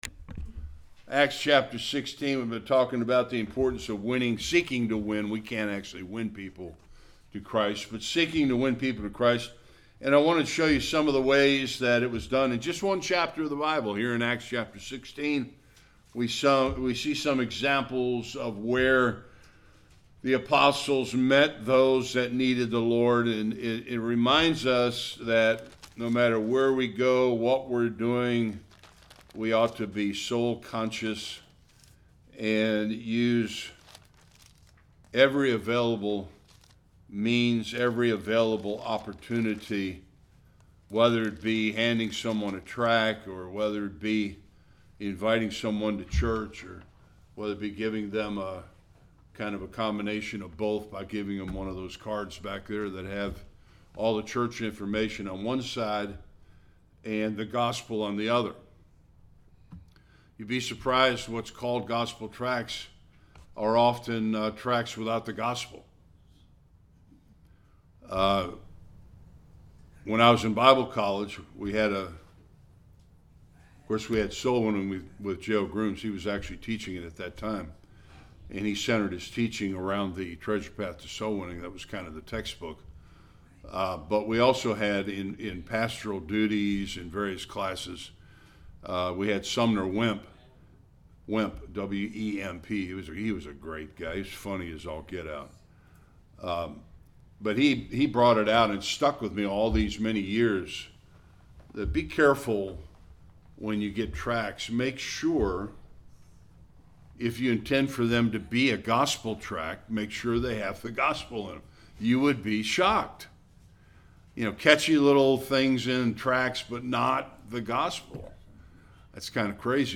Various Passages Service Type: Sunday School A clear presentation of our testimony is essential in our soulwinning efforts.